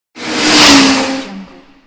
Race Car Speeding Transition Meme sound effects free download
Race Car Speeding Transition- Meme Sound Effect